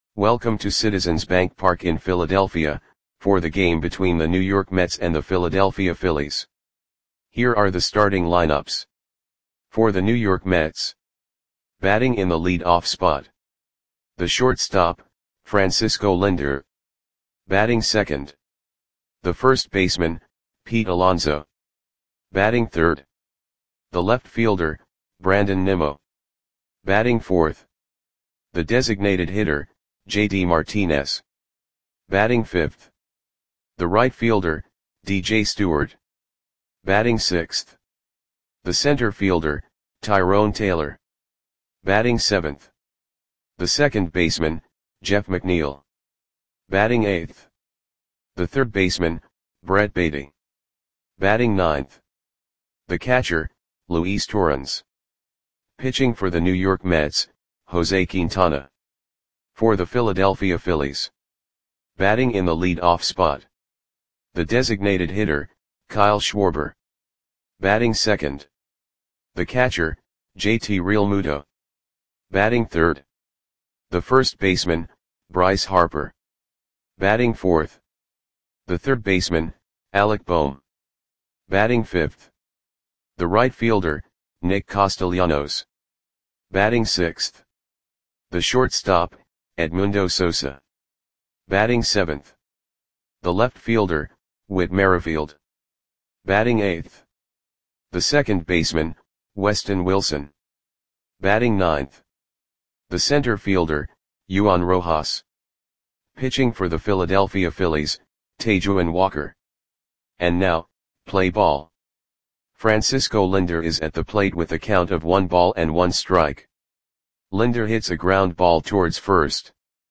Audio Play-by-Play for Philadelphia Phillies on June 9, 2024
Click the button below to listen to the audio play-by-play.